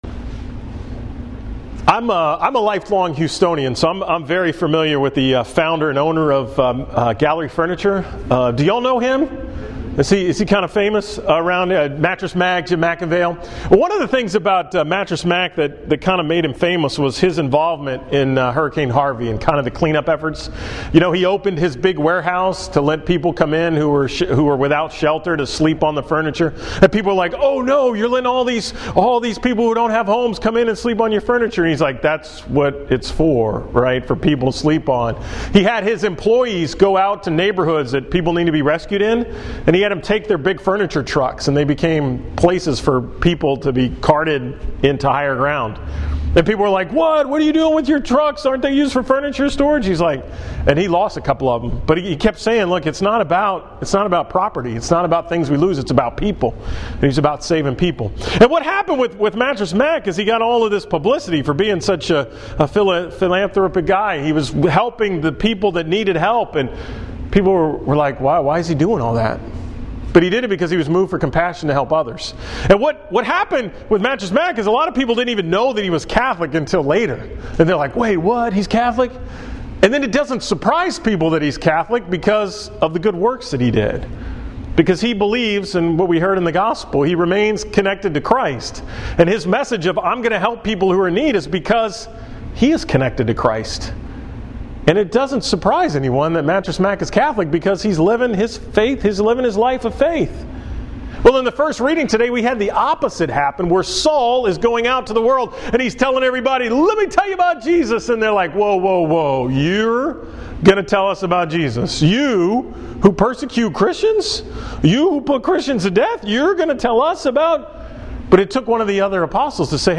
From Mass at the Texas A&M Galveston Student Center on April 29, 2018